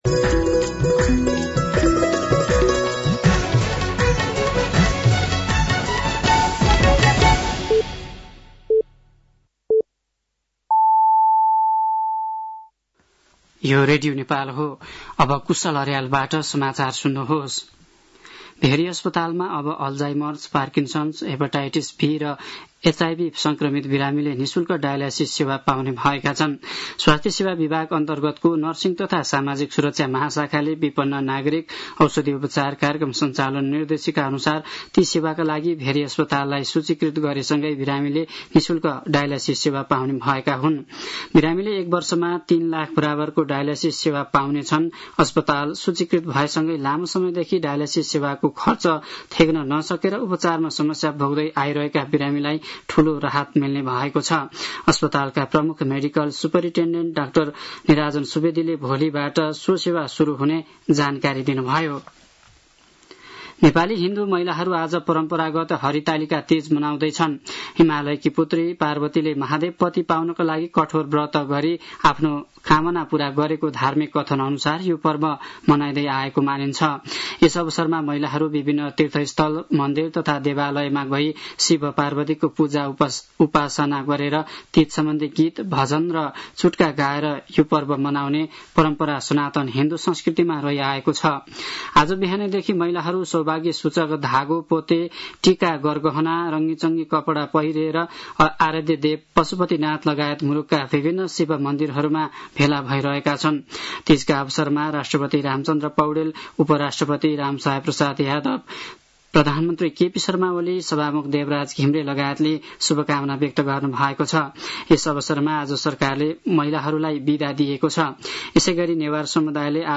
An online outlet of Nepal's national radio broadcaster
साँझ ५ बजेको नेपाली समाचार : १० भदौ , २०८२